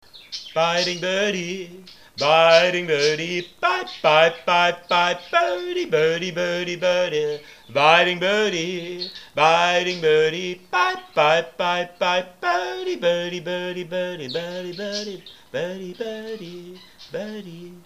And they respond.
[n.b. Phlegm in my throat made singing difficult.]
[n.b. The Budgies calls had to be increased in volume,
thus increasing background computor hum.]
My Singing To Them - 4th November 2007 [1].